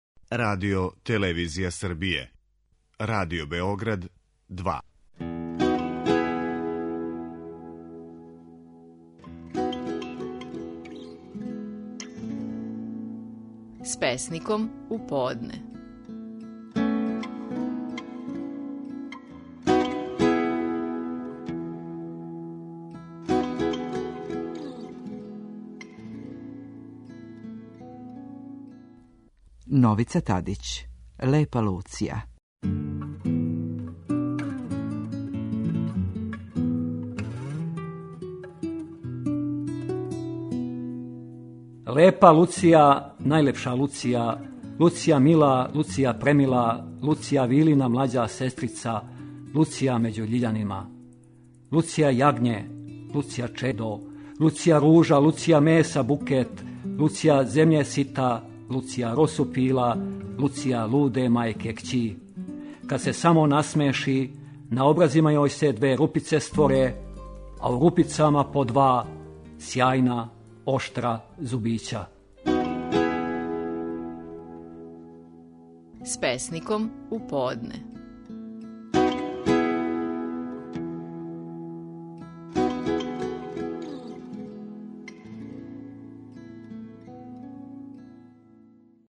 Наши најпознатији песници говоре своје стихове
Новица Тадић говори своју песму "Лепа Луција".